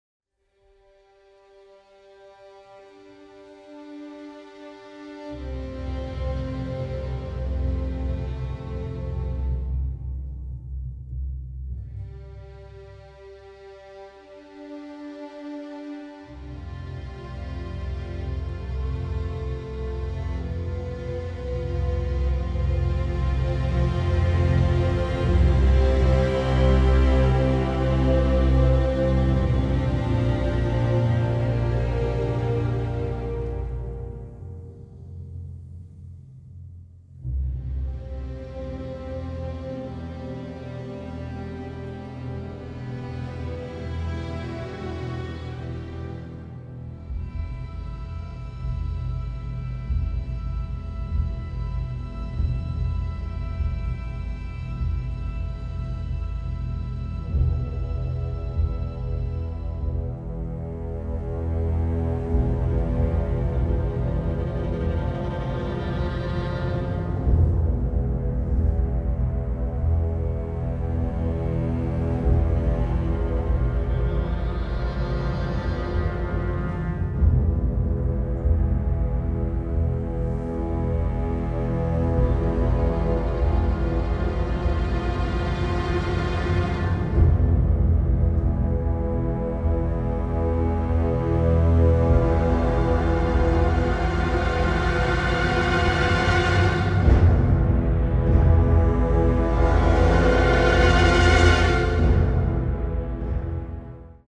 ominous score